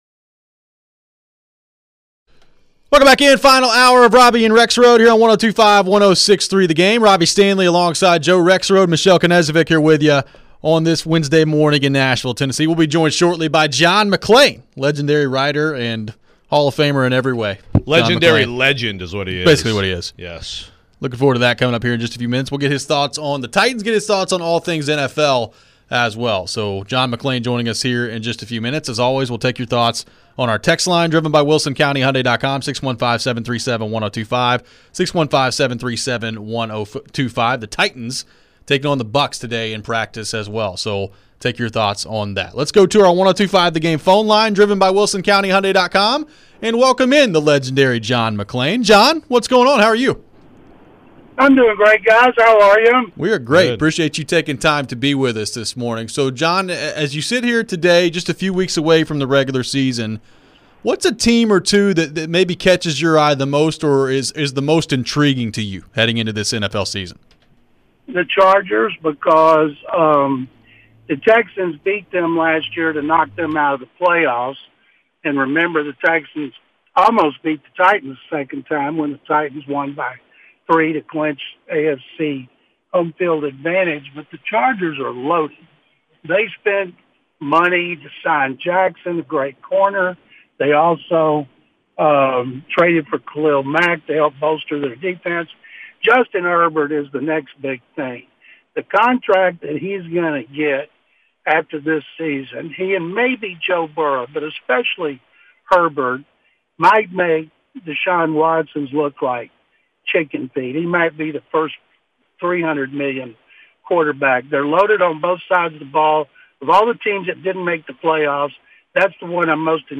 hops on headset live from camp.